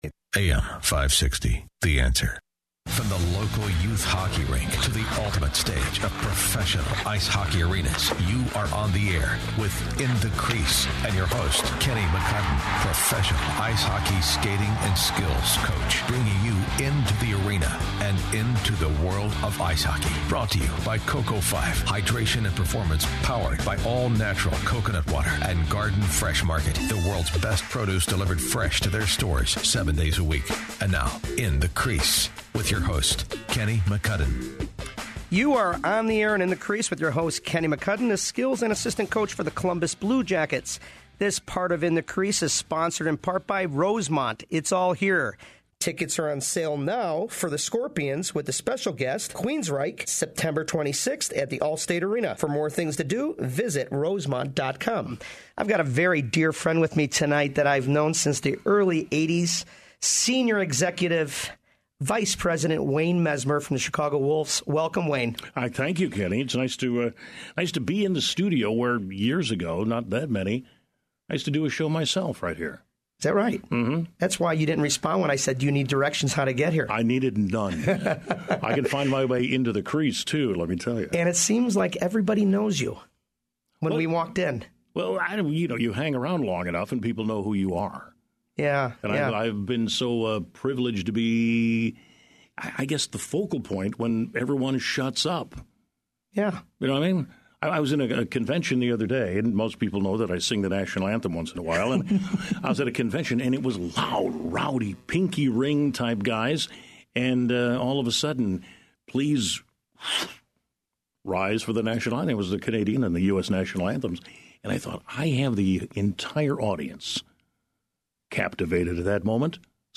Radio Interview AM560 The Answer